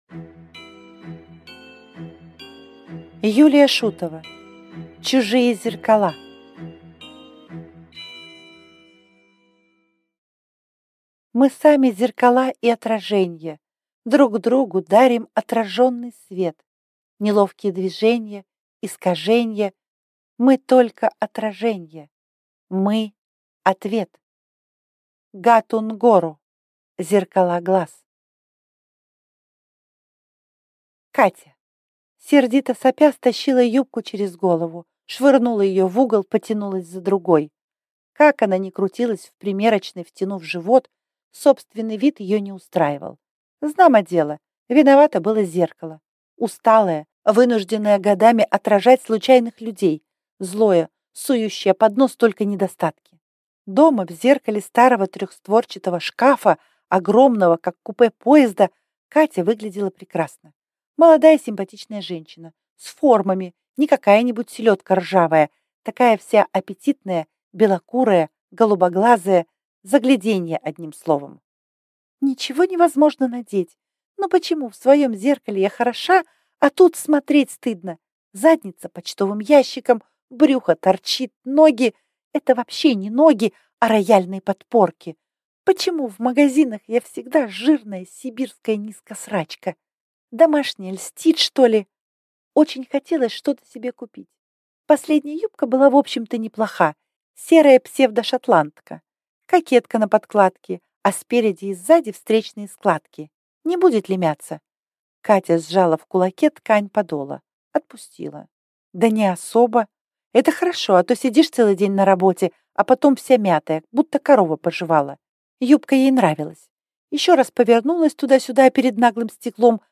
Аудиокнига Чужие зеркала | Библиотека аудиокниг